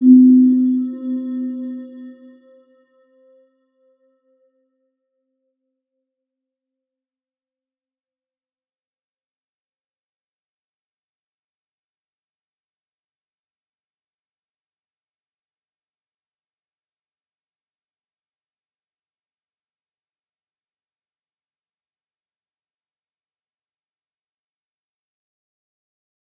Round-Bell-C4-p.wav